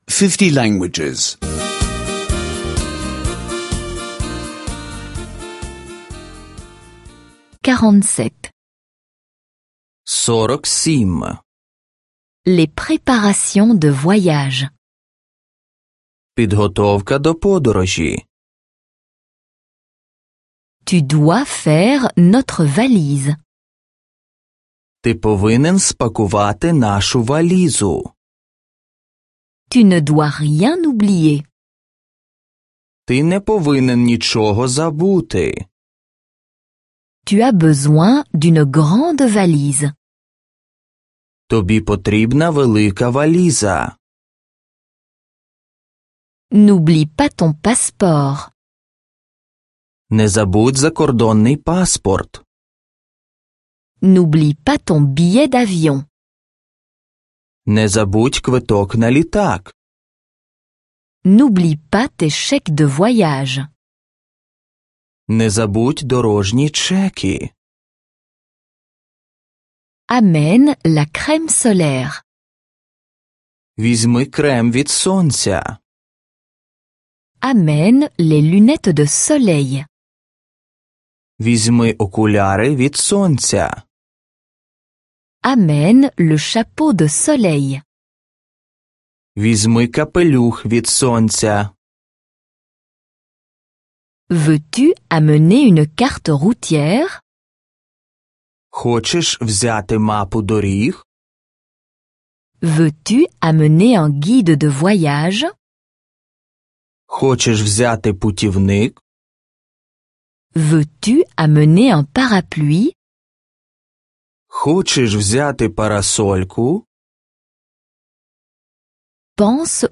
Cours audio de ukrainienne (téléchargement gratuit)